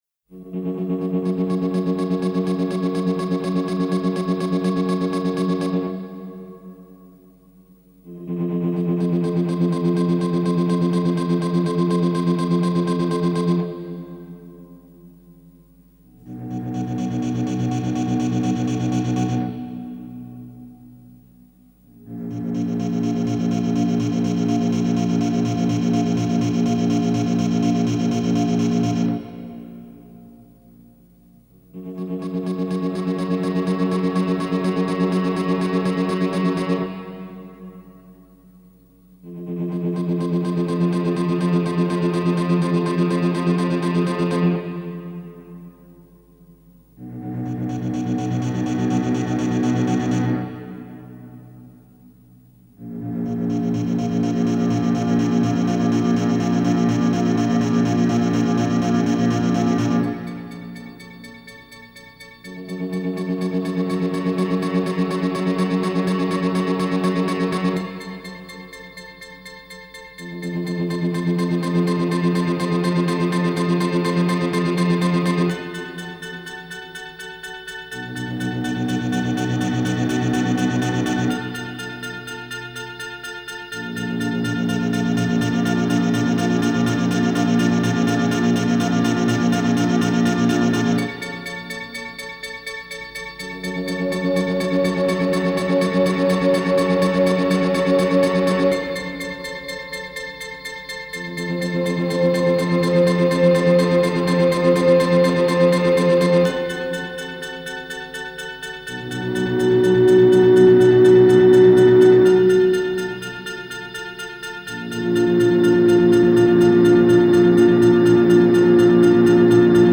Alternative Ambient Electronic